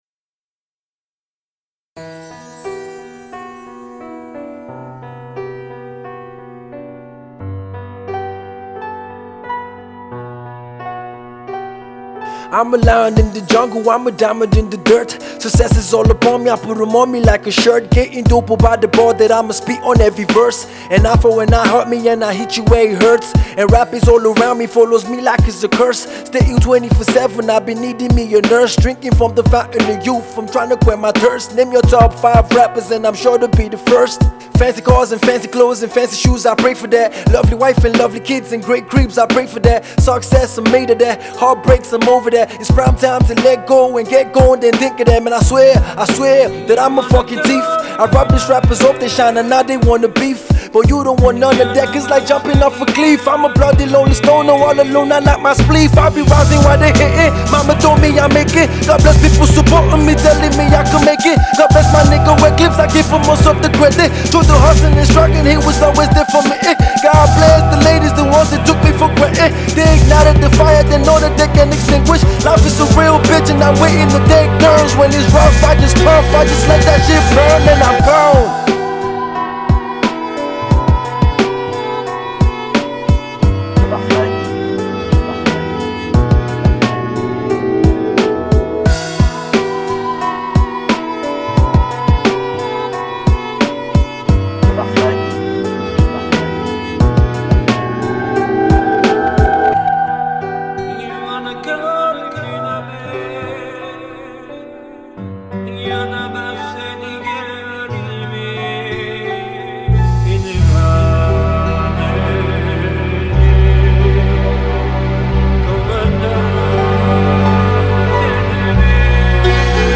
Hip-Hop Music